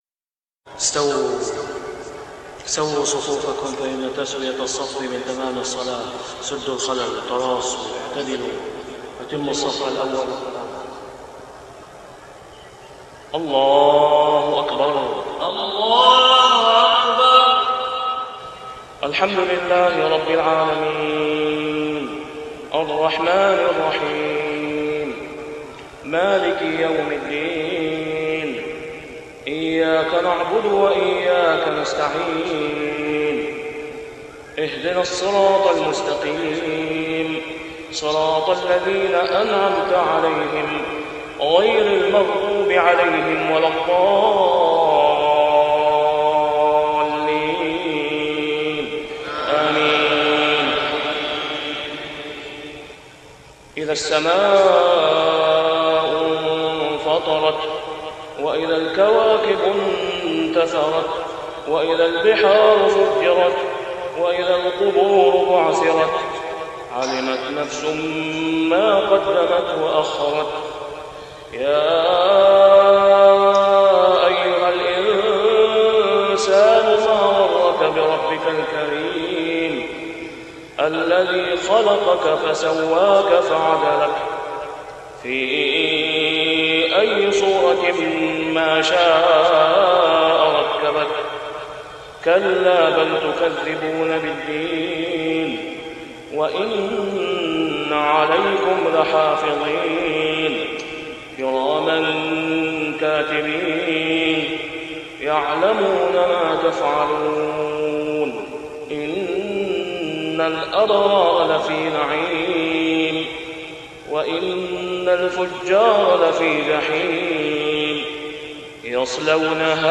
( صلاة العشاء و العام غير معروف ) | سورة الانفطار كاملة > 1422 🕋 > الفروض - تلاوات الحرمين